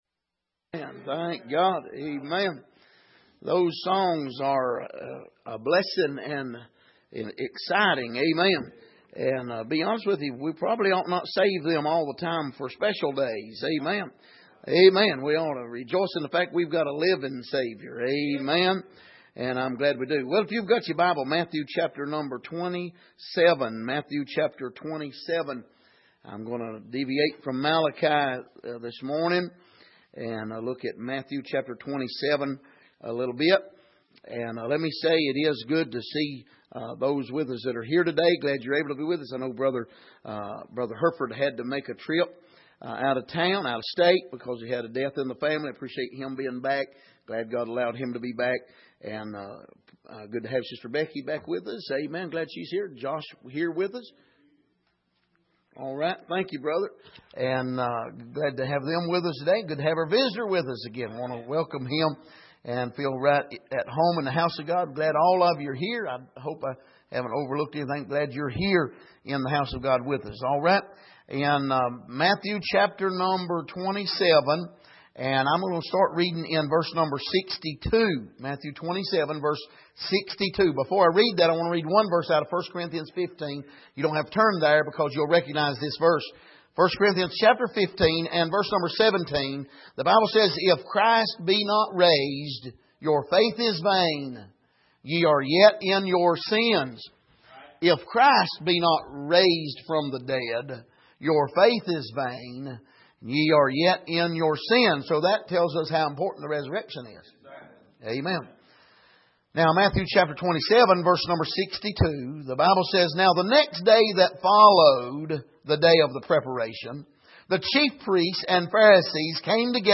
Matthew 27:62-66 Service: Sunday Morning Are You Sure About The Resurrection?